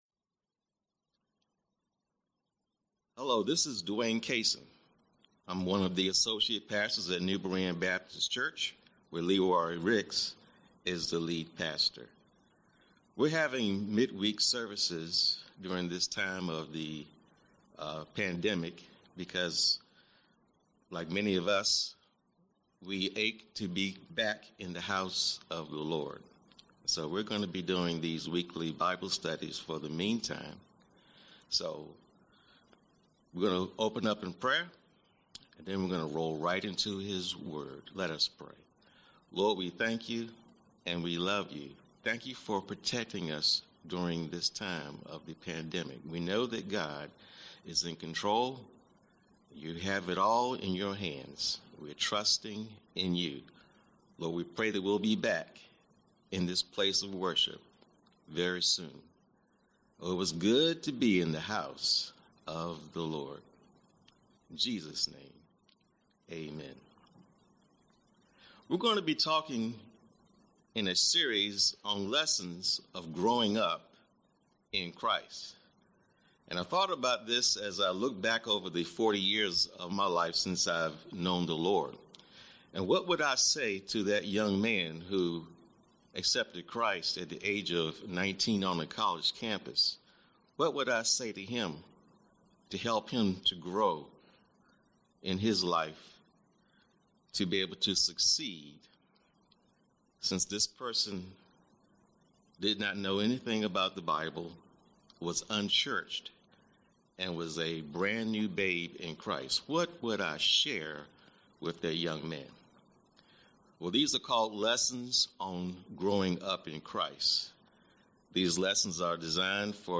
Mid-Week Bible Study